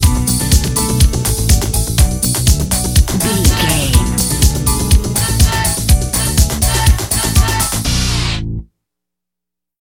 Dorian
Fast
drum machine
synthesiser
electric piano
Eurodance